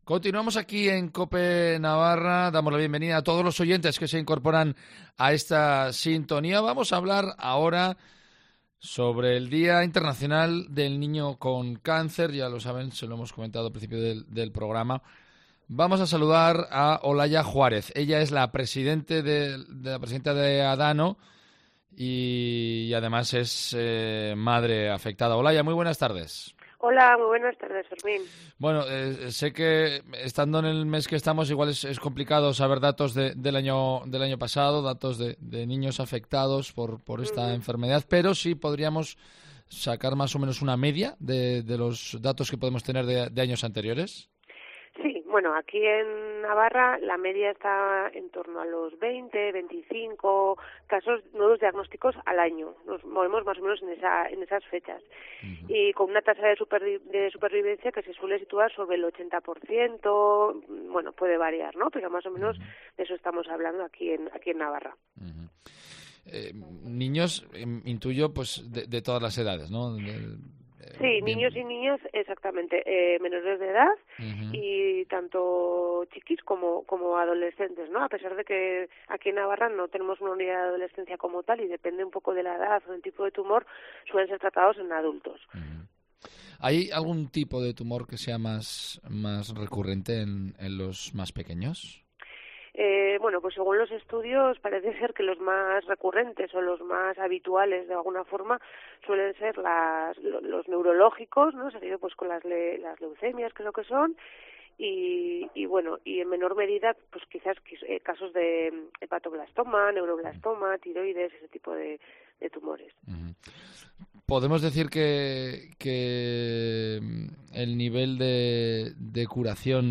En Cope Navarra